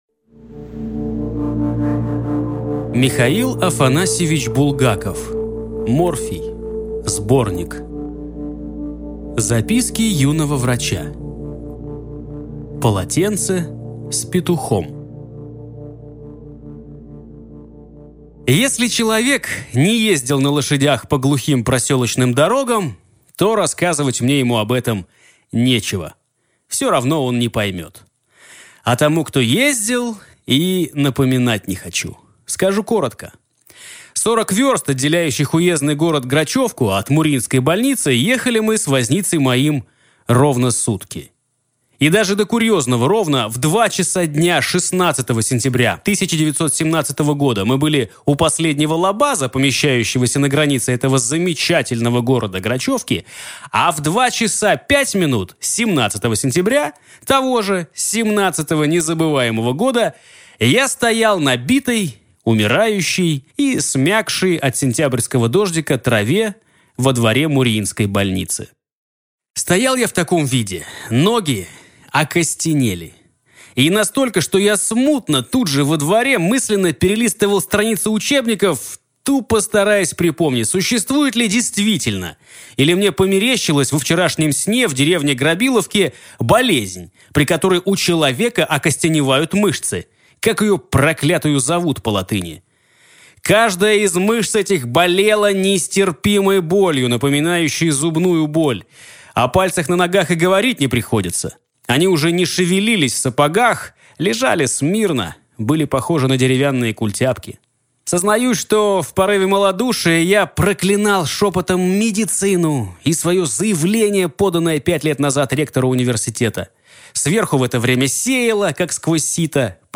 Аудиокнига Морфий (сборник) | Библиотека аудиокниг